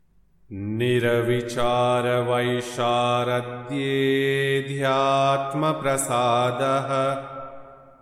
Yoga Sutra 1.47 | Nirvichāra-vaiśhāradhye'dhyāt...| Chant Sutra 1.47